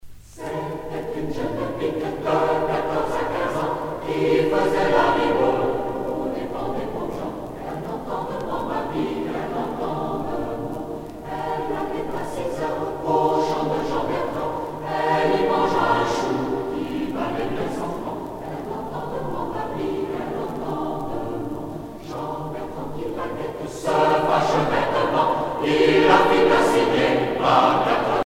Genre laisse Artiste de l'album Franco-Allemande de Paris (chorale)
Pièce musicale éditée